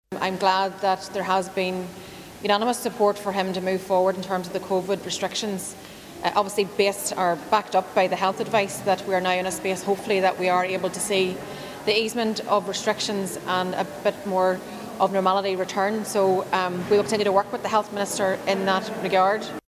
Sinn Fein’s Stormont leader Michelle O’Neill says Mr Swann had a “big job ahead” of him: